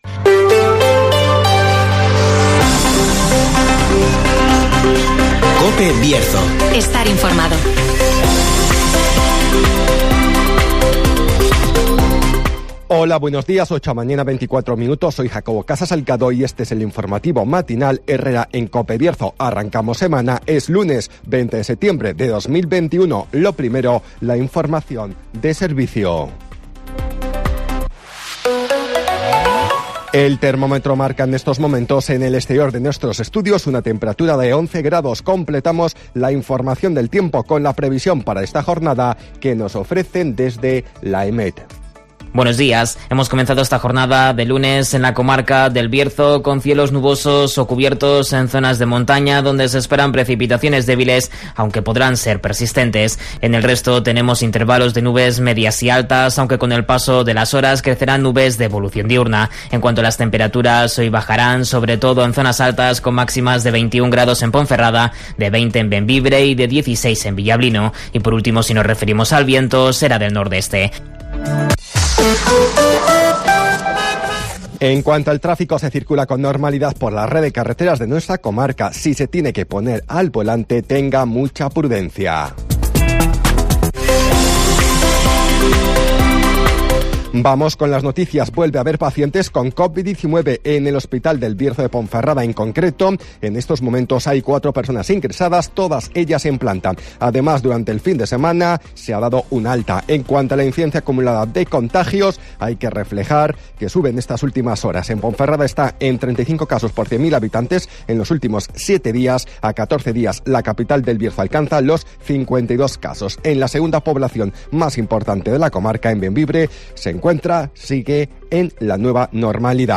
AUDIO: Repaso a la actualidad informativa del Bierzo. Escucha aquí las noticias de la comarca con las voces de los protagonistas.